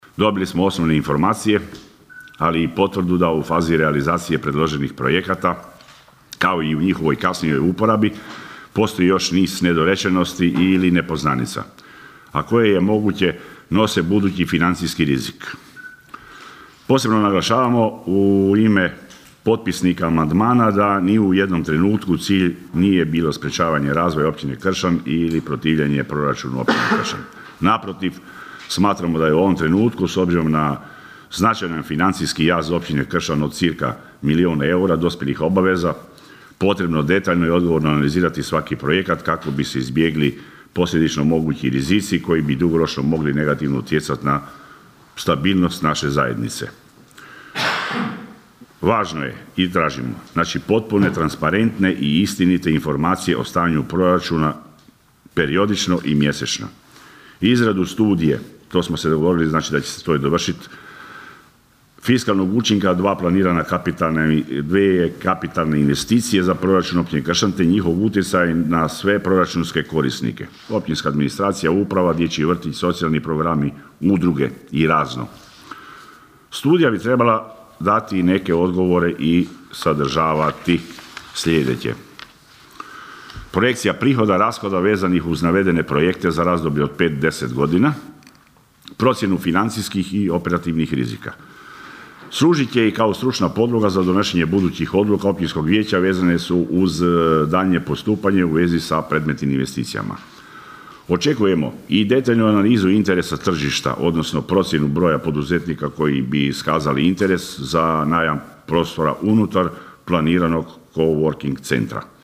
Budući da nismo željeli preuzeti odgovornost i izglasati predloženi Proračun bez potpune i jasne slike o navedenim projektima, zatražili smo radni sastanak kako bismo dobili dodatna pojašnjenja i odgovore na naša pitanja“, pojasnio je sinoć na sjednici nezavisni vijećnik Valdi Runko.